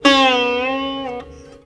SITAR LINE57.wav